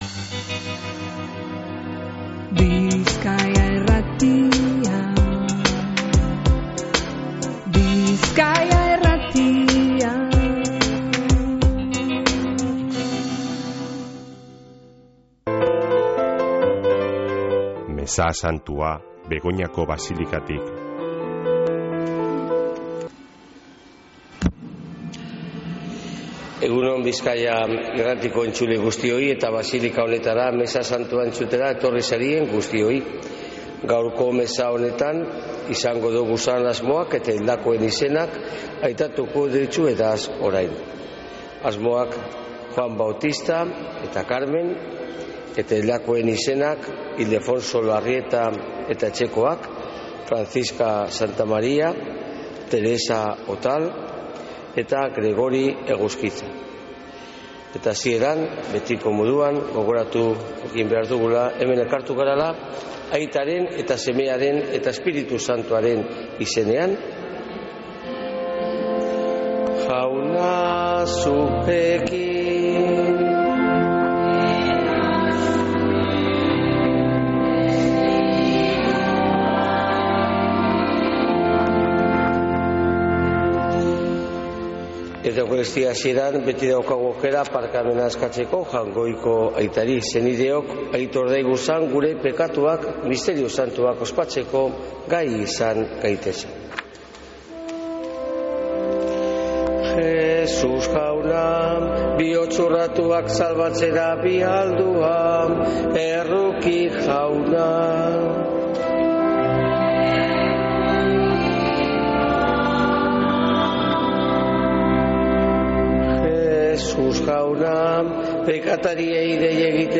Mezea Begoñako basilikatik | Bizkaia Irratia
Mezea (25-07-09)